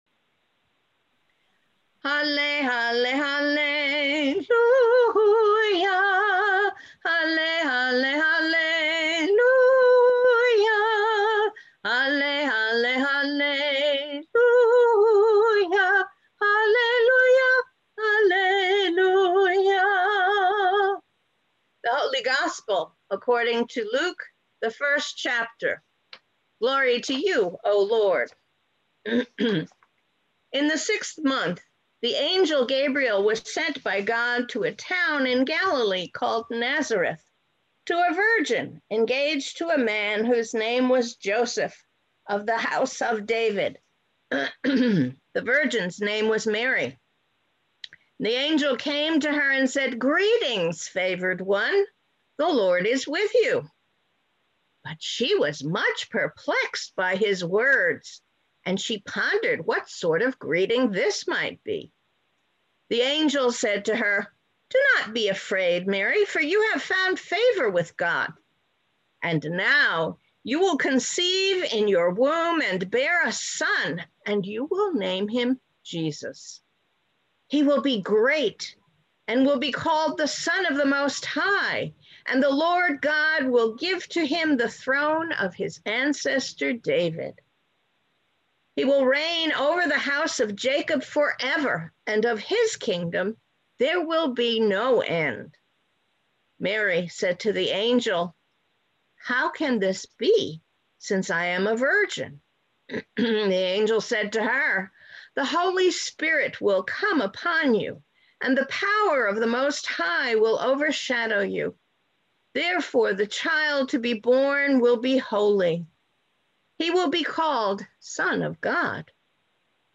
Sermons | Lutheran Church of the Epiphany and Iglesia Luterana de la Epifania